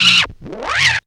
CAT SKIDZ.wav